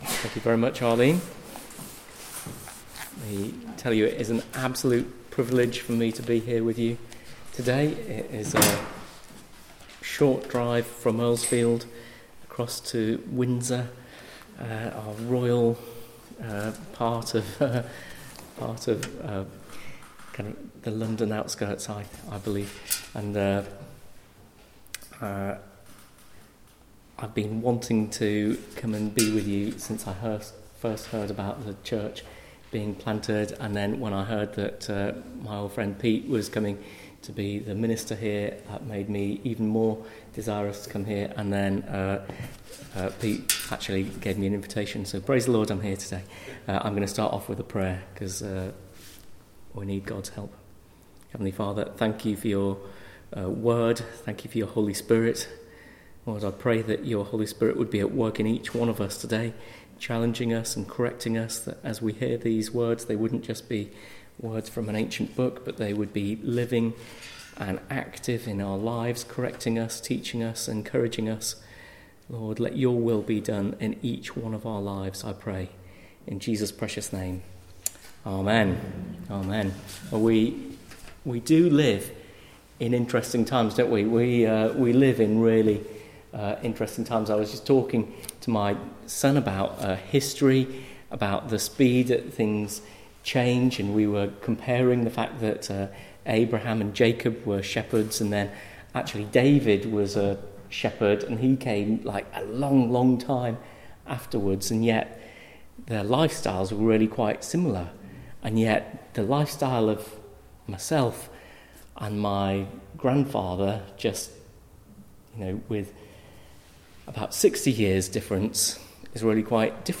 1-9 Service Type: Weekly Service at 4pm Bible Text